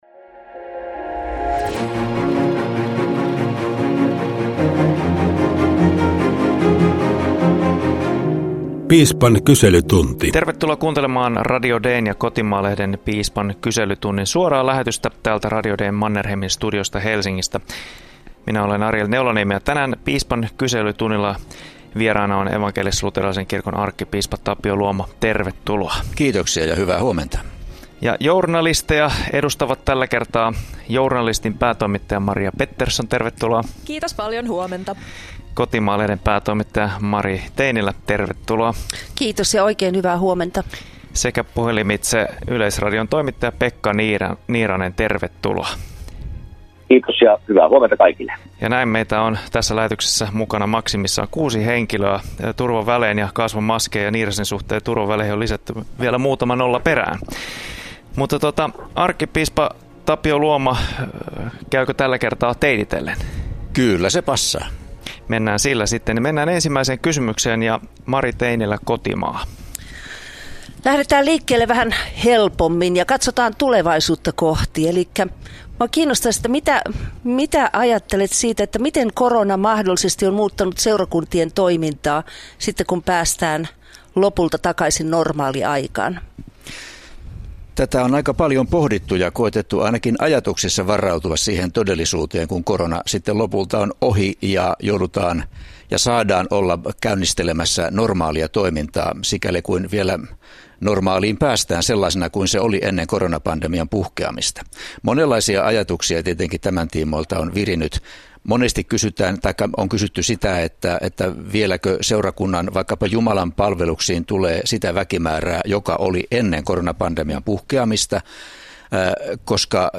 Radio Dein ja Kotimaa-lehden Piispan kyselytunti suorittaa journalistisen piispan tarkastuksen kerran kuukaudessa. Tällä kertaa vastausvuorossa on arkkipiispa Tapio Luoma.